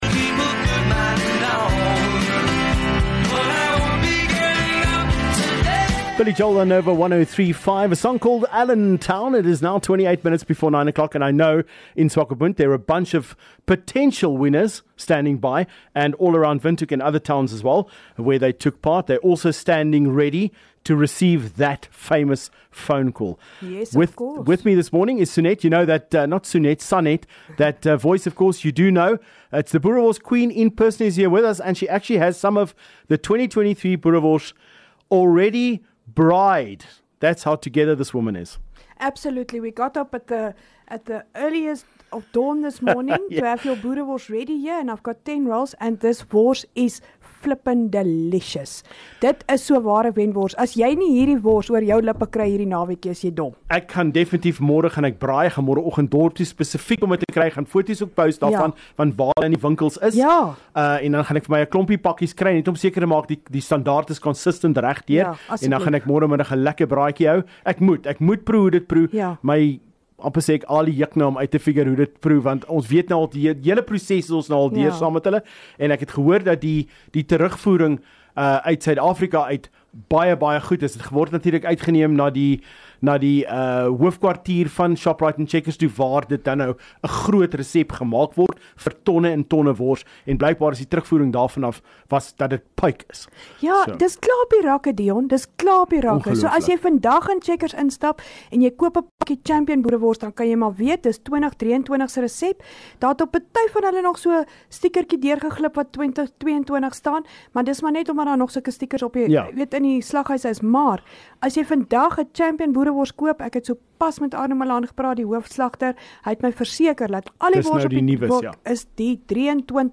The top three in the competition were called live on Nova1035 thos morning...